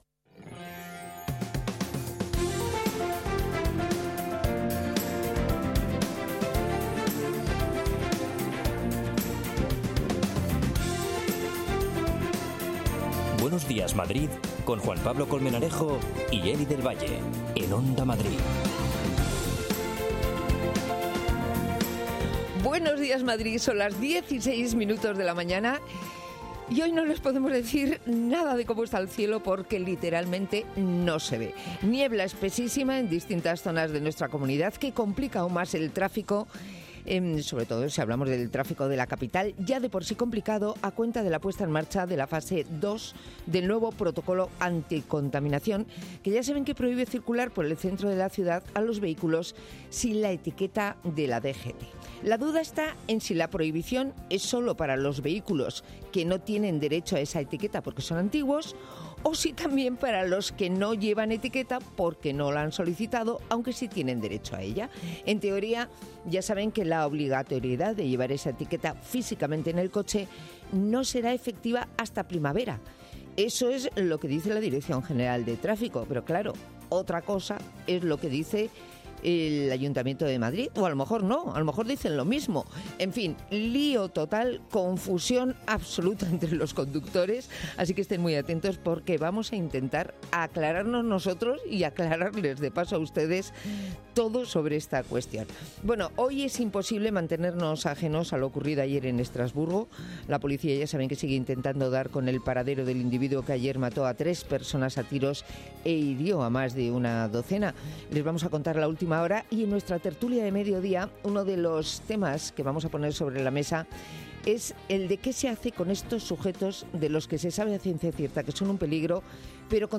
Aclaramos detalles y salimos a la calle para saber si la ciudadanía ha tenido problemas o dudas sobre la aplicación de esta normativa.
Salimos a la calle para preguntar sobre "Macrofiestas".